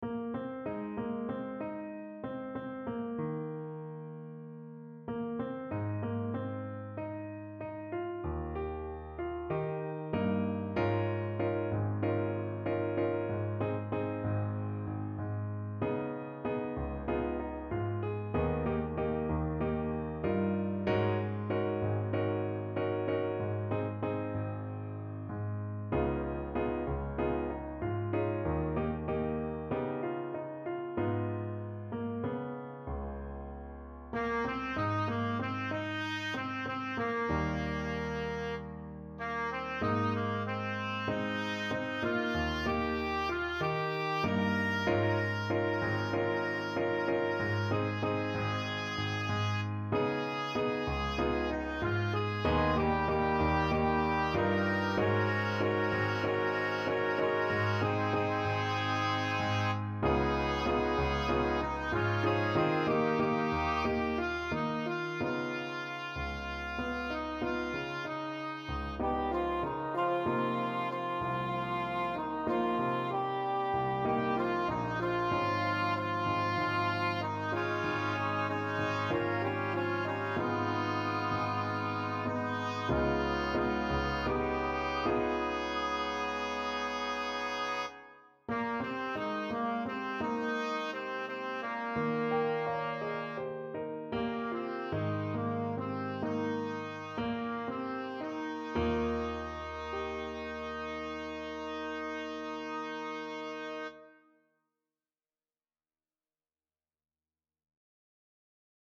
SATB, piano
Rescored for mixed choir